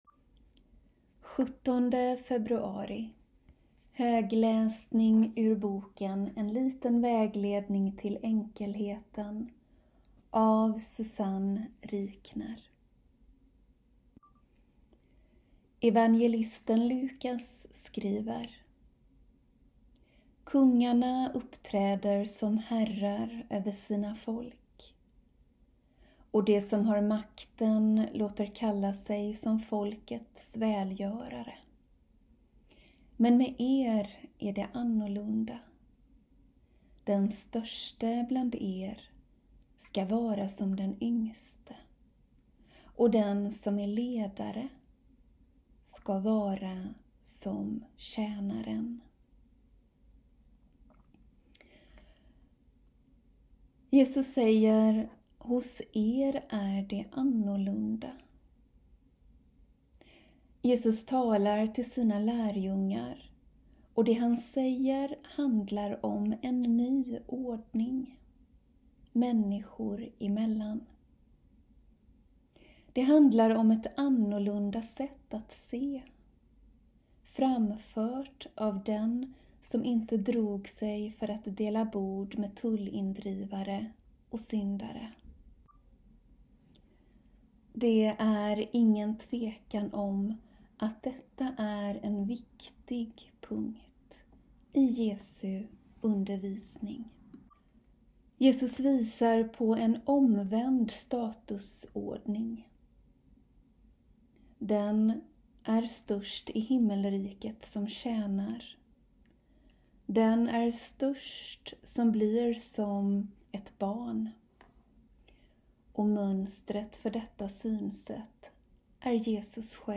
Daglig läsning från klostret ur boken En liten vägledning till enkelheten av Susanne Rikner